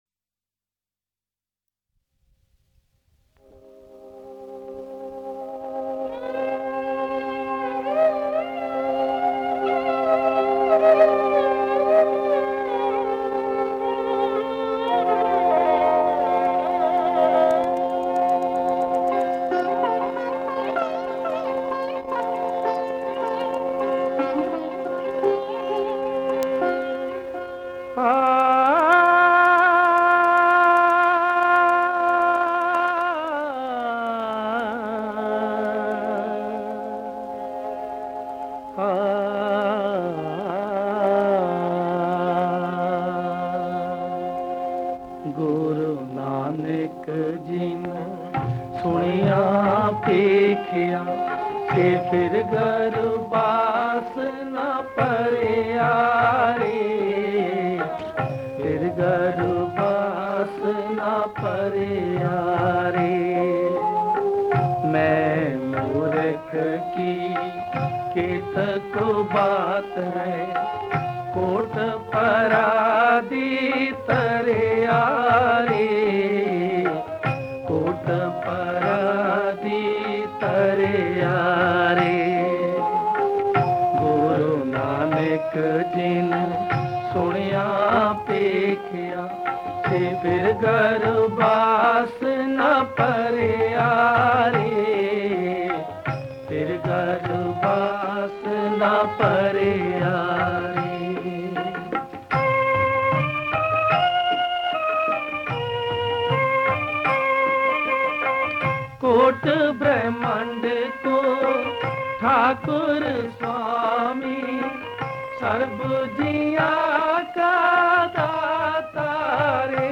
Genre: Shabad Gurbani Kirtan